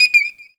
pgs/Assets/Audio/Alarms_Beeps_Siren/beep_13.wav at master
beep_13.wav